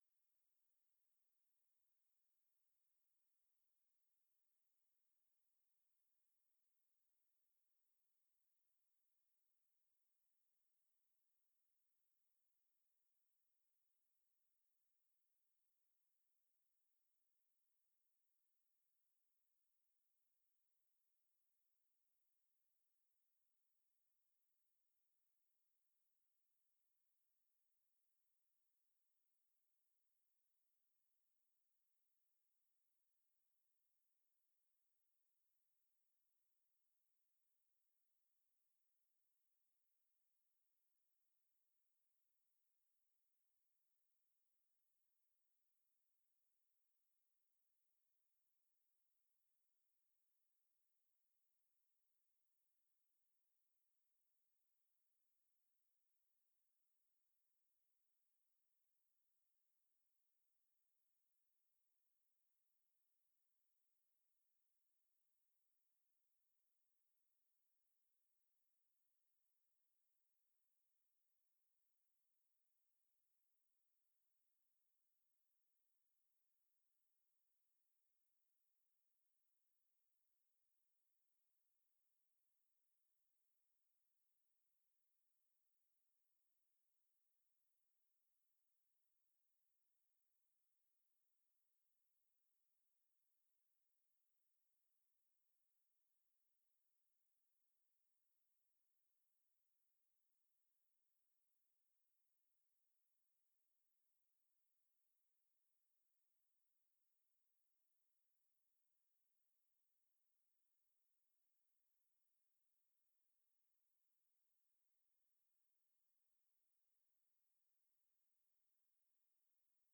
Quaker Christianity 101: What does it mean for us to be Friends of Jesus? This spring, Berkeley Friends Church will be exploring the basics of Christianity – the stories of the Bible, the power of the gospel, and the life of community in Jesus. We hope you’ll join us for the following sessions, on Sundays from 12:30-2:00pm in the Fellowship Hall: 3/31– Who is Jesus?, 4/7 – What is the Church?, 4/14 – The Bible and the Word of God, 5/5 – The Gospel Applied 5/12 – Exploring our Spiritual Gifts, 5/26 –Letting Our Light Shine